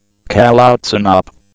IDG-A32X/Sounds/GPWS/callouts-inop.wav at a4af9156aac4f1a225c2fc06f1f4873eb29aefb5
callouts-inop.wav